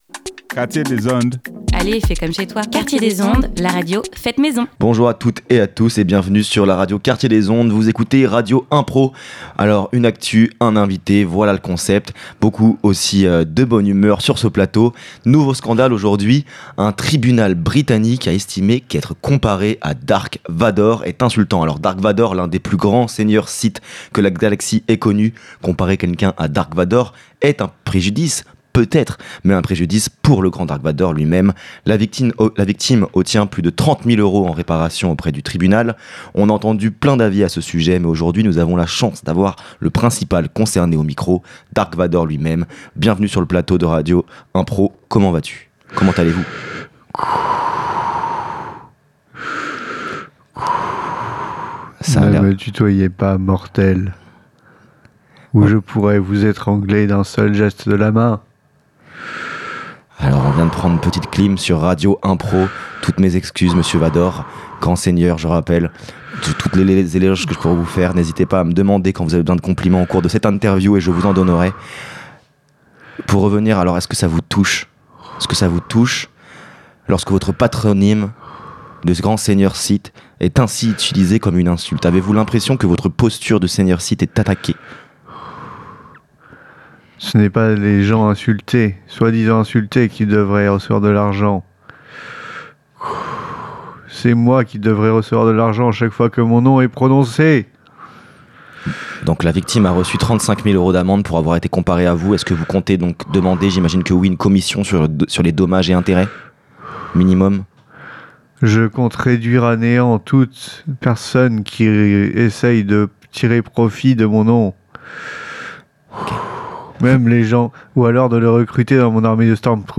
Au micro de Radio Impro, Dark Vador en personne réagit à l’utilisation de son nom comme propos injurieux.
Tout cela crée des interviews tantôt humoristiques, tantôt poétiques, tantôt engagées et parfois un peu de tout ça !